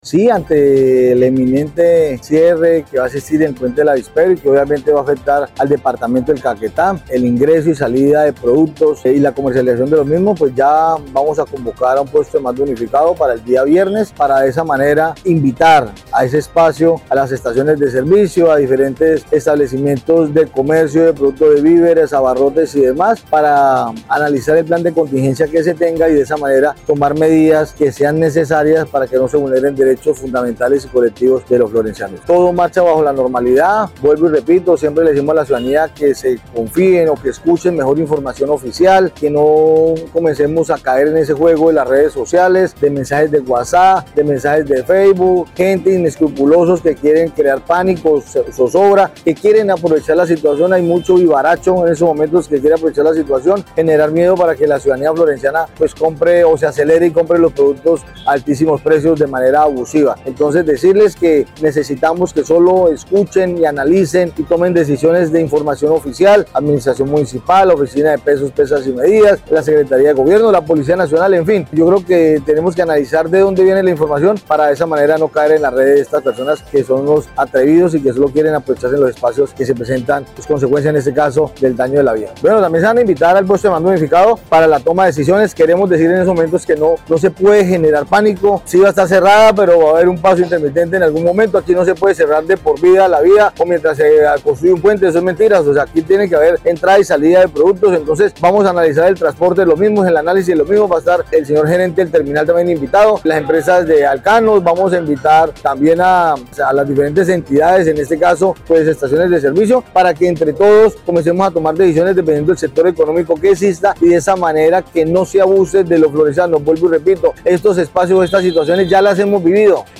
Así lo dio a conocer Carlos Mora Trujillo, secretario de gobierno municipal, quien dijo que, se debe evitar falsos mensajes de redes sociales para no caer en especulaciones y sobrecostos en alimentos y productos como el combustible y gas domiciliario, atendiendo solo canales oficiales de información.